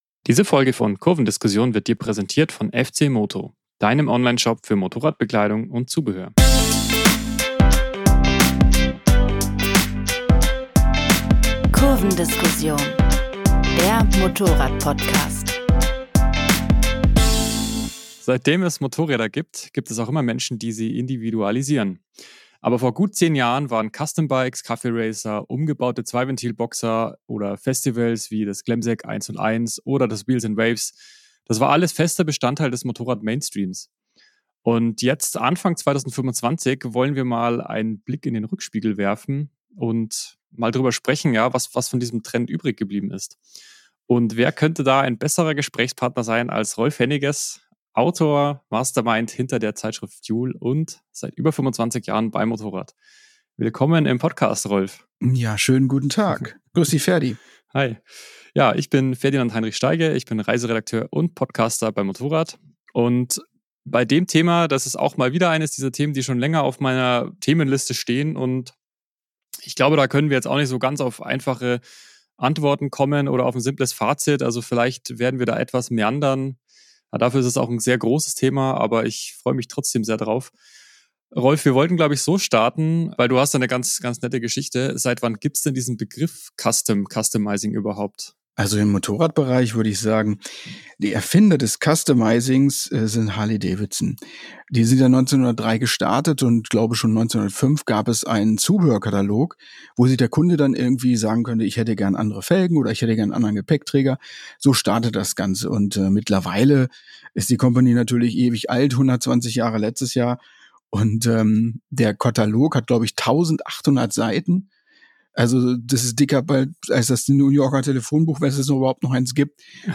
Kurvendiskussion, das sind Benzingespräche am MOTORRAD-Stammtisch, mit Redakteuren und Testern. Es geht um aktuelle Modelle, Trends, Schrauberphilosophie und alles, was uns sonst bewegt. Auch die ein oder andere Anekdote aus dem Redaktionsalltag, die es im Heft nicht zu lesen gibt.